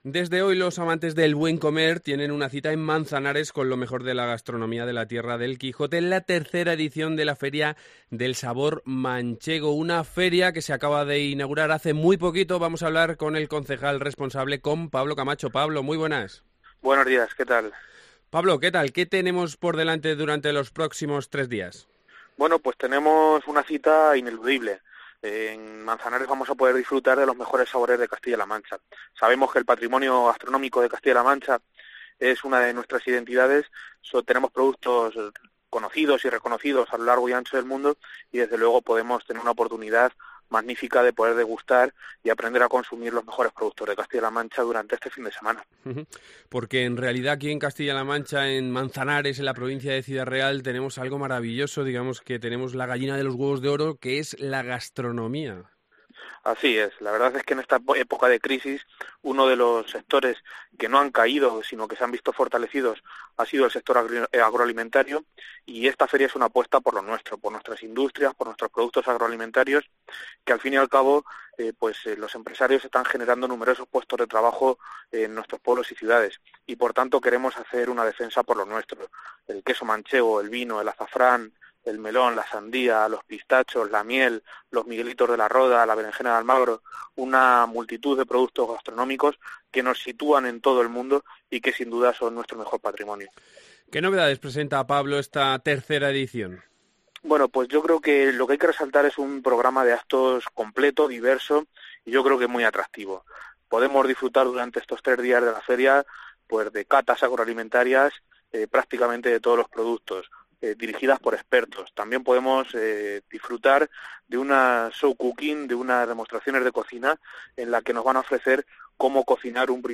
El queso manchego, el vino, la miel y el azafrán están invitados a la cita, que además incluirá catas, talleres de cocina y un concurso de cortadores de jamón. Hablamos con el concejal de Ferias del Ayuntamiento de Manzanares Pablo Camacho.